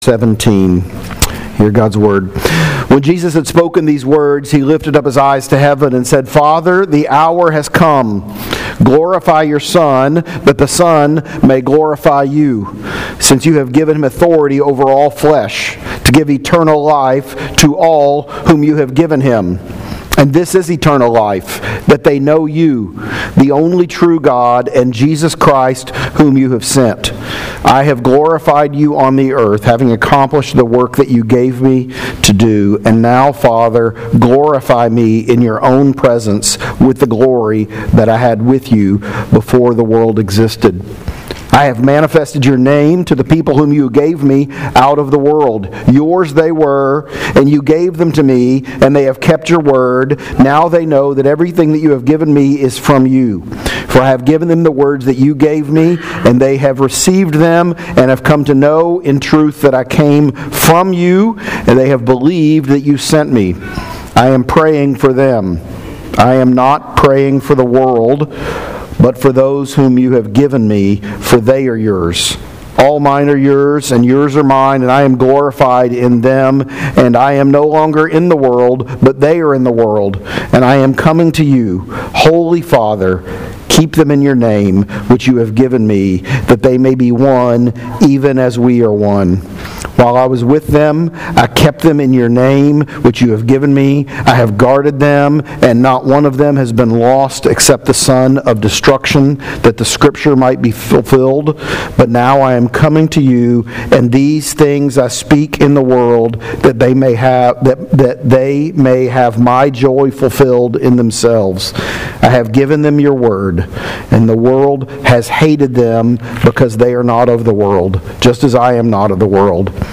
John 17 Sermon